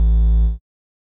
bass
添加三个简单乐器采样包并加载（之后用于替换部分音效）